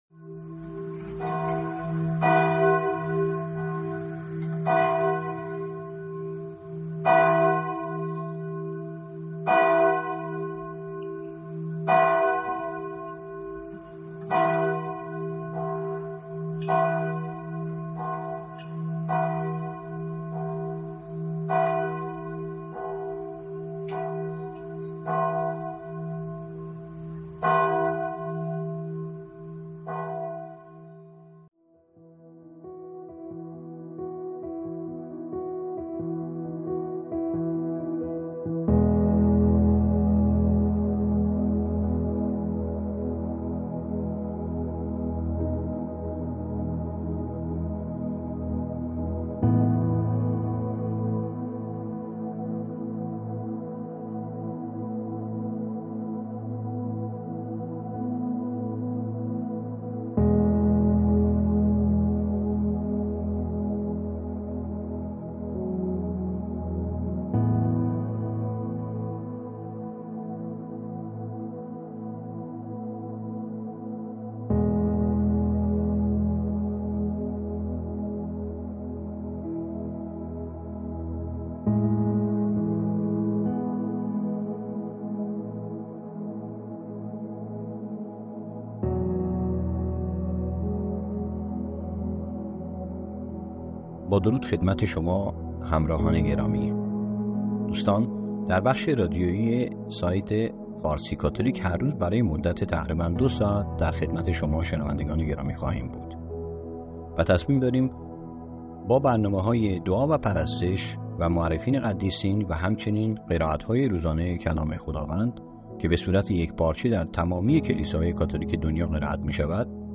حدود دو ساعت برنامه های صوتی روزانه فارسی کاتولیک به شکل فایل، شامل انواع دعا،قرائت های کلیسایی و... برای شما عزیزان آماده گشته است